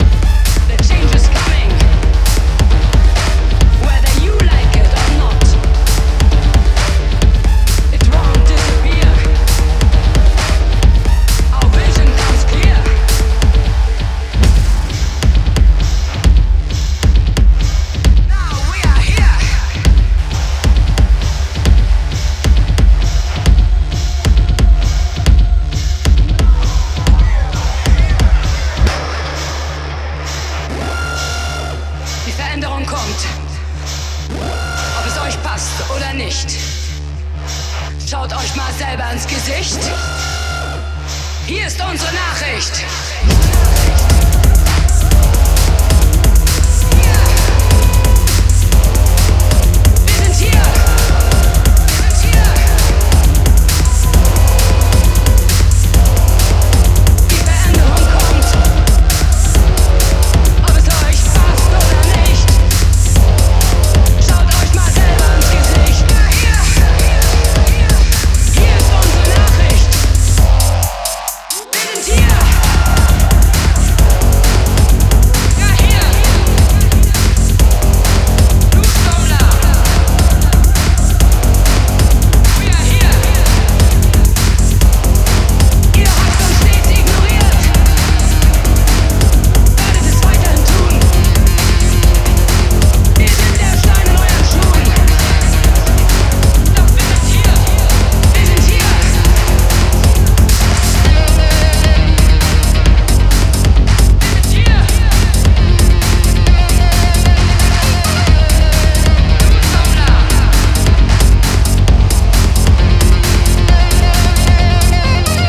Electro Techno Wave